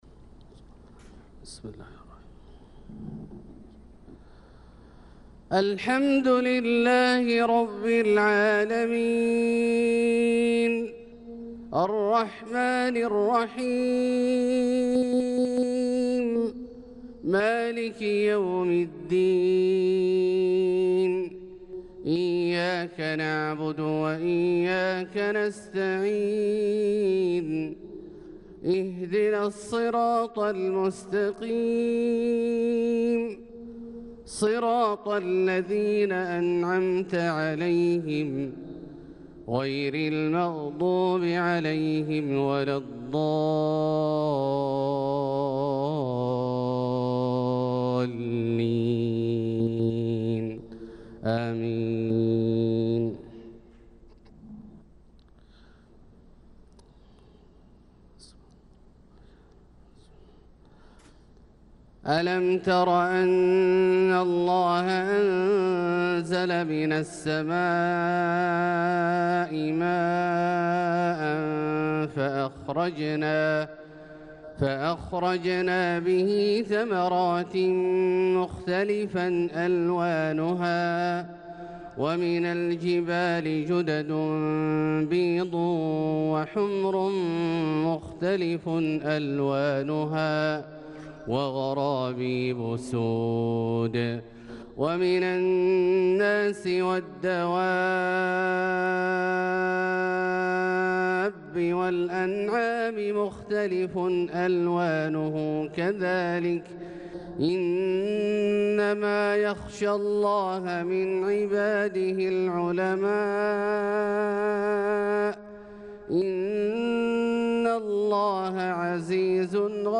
صلاة الفجر للقارئ عبدالله الجهني 15 ذو القعدة 1445 هـ